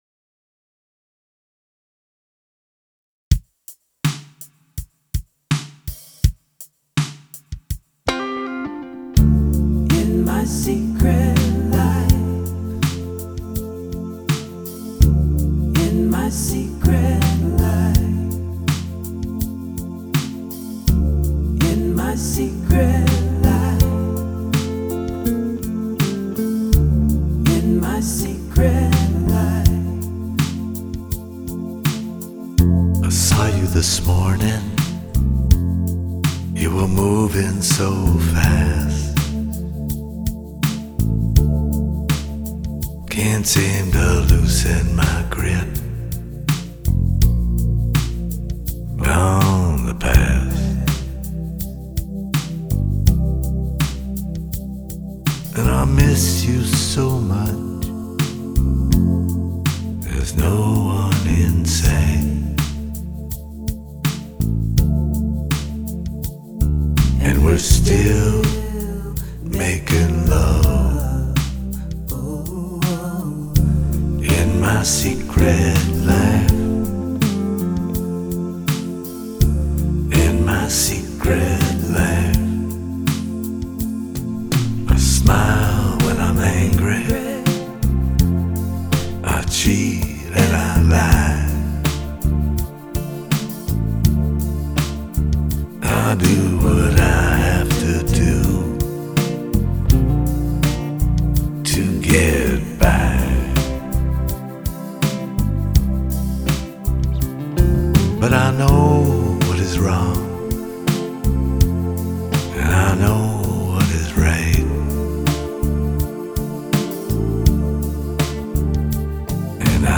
第一耳——这声音怎么像含了一口痰一样，好难听啊！